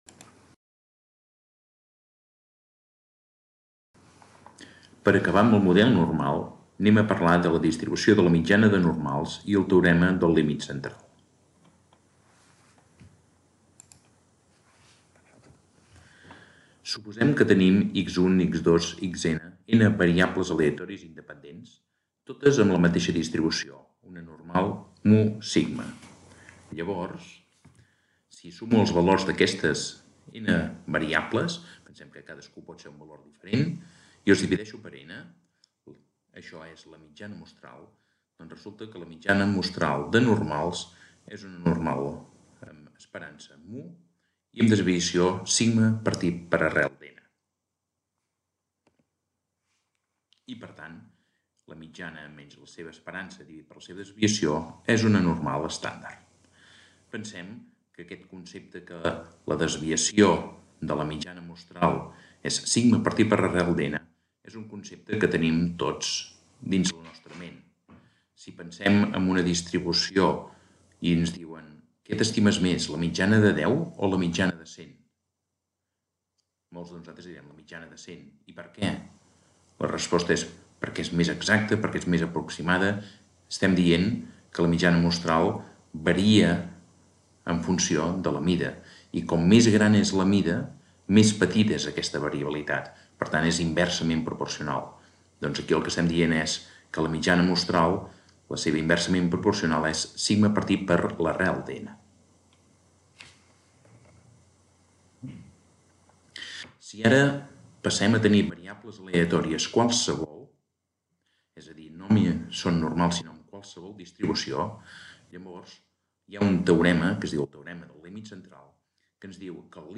Model normal: distribució mitjana mostral, teorema del límit central i distribucions t-Student, Xi2 i F   2020  Text Complet  7874.mp3 10.17 Mb | MP3     Compartir  </>  Lesson of the subject Theory of Statistics on the Normal Model. The sampling mean distribution, the central limit theorem, the t-Student and Xi2 and F distributions  Aquest document està subjecte a una llicència Creative Commons: Reconeixement – No comercial – Compartir igual (by-nc-sa) Mostra el registre complet de l'element